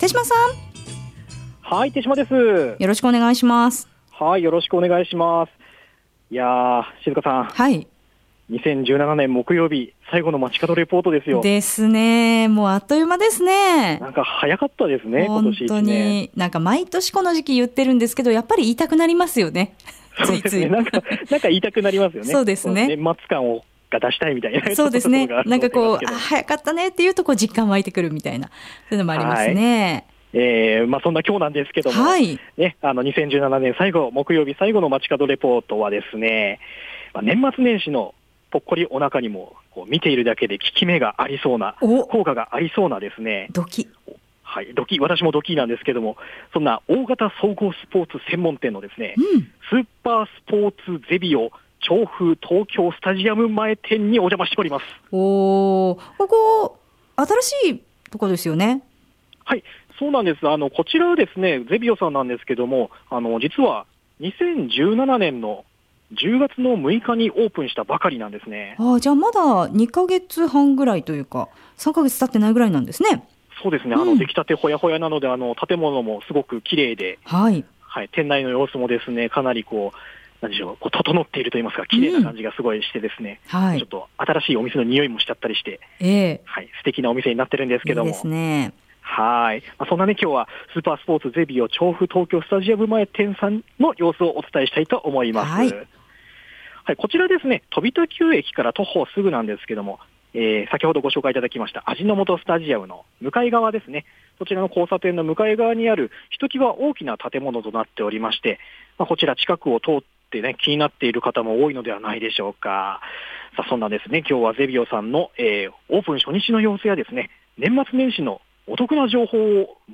本日の放送音声はコチラ↓